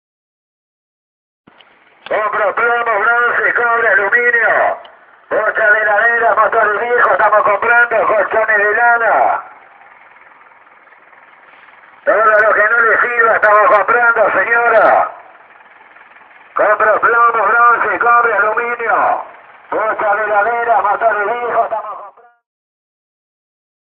Habla a los gritos creyendo que de ese modo demuestra poder, y a lo único que nos recuerda es al vendedor ambulante que pasa por los barrios ofreciendo la compra de chatarra o todo lo que tengas para vender:
Aunque estés en un piso alto y cierres herméticamente las ventanas esa voz con un megáfono no deja de alterarnos, pero escuchar a un presidente que encima de hablar del mismo modo que el chatarrero tiene voz de pito, te señala con el dedito y te reta mintiendo que es el líder de la moral es opcional y podemos apagar los medios que lo difunden.
Audio-de-vendedor-callejero-con-megafono-en-camion..mp3